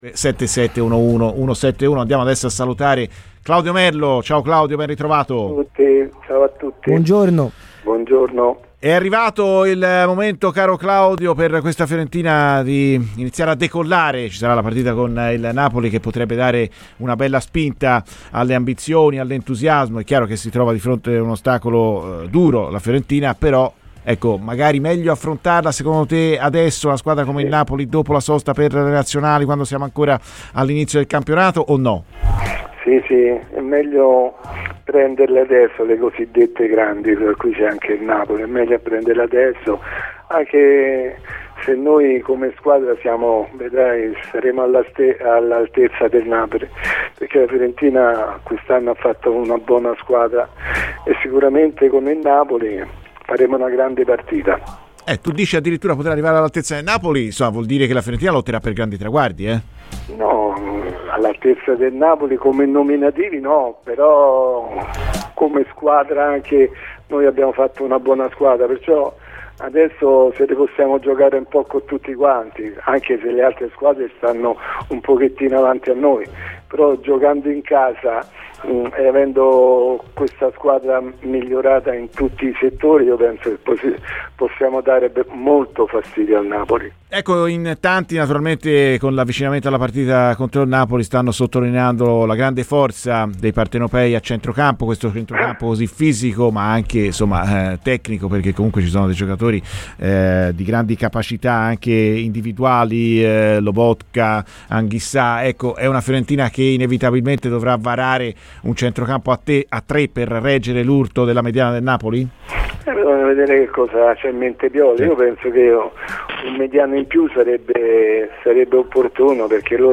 Claudio Merlo, ex giocatore della Fiorentina, ha parlato ai microfoni di Radio Firenzeviola.